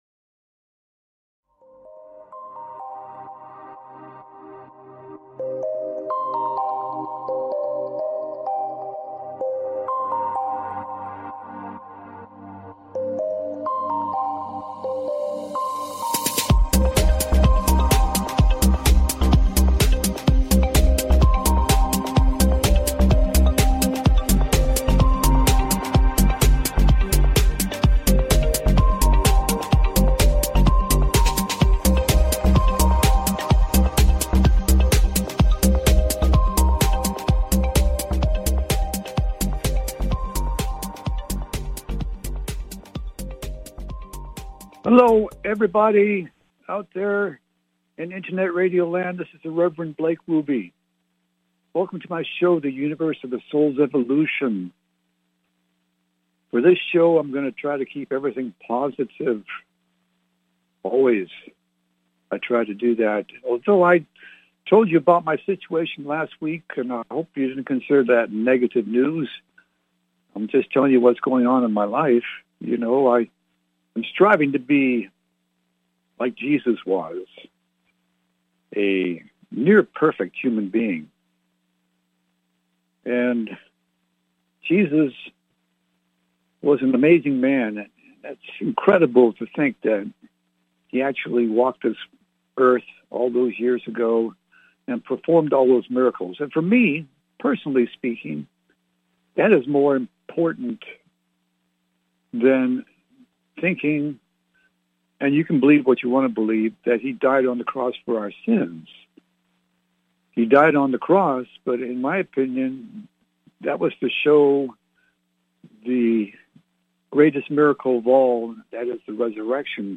Universe of the Souls Evolution Talk Show